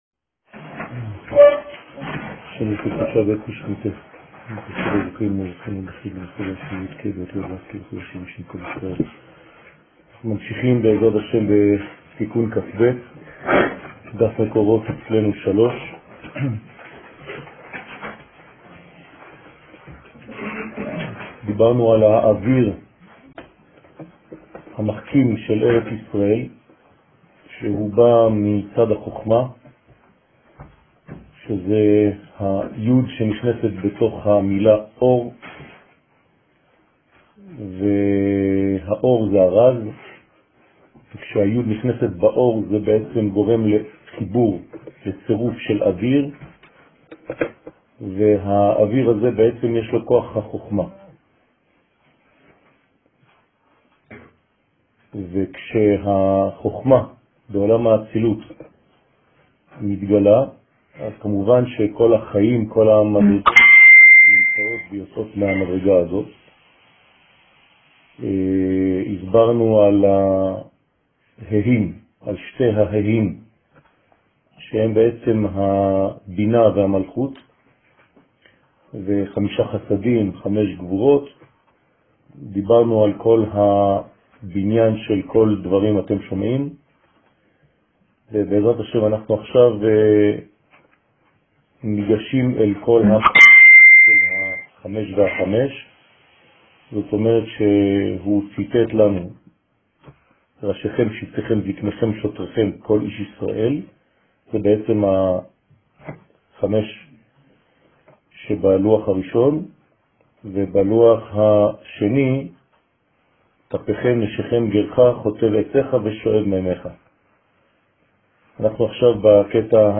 שיעור לילה - תיקוני זוהר כב
שיעורים